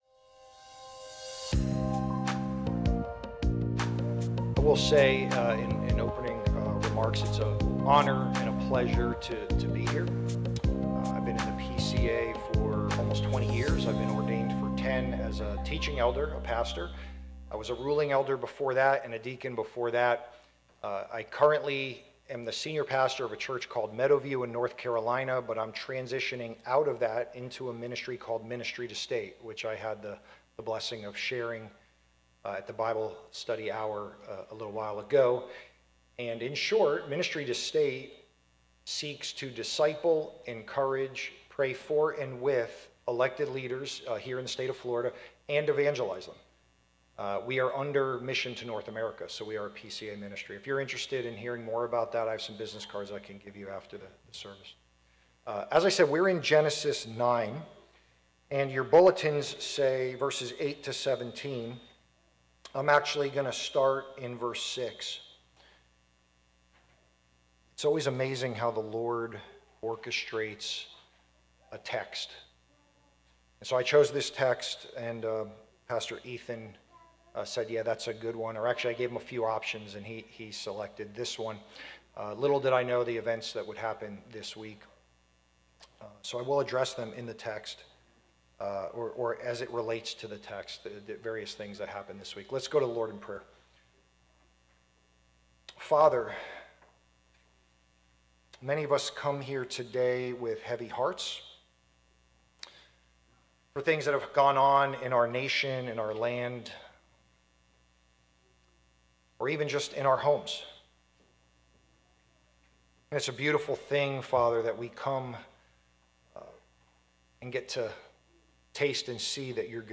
Charlie-Kirk-Sermon-audio.mp3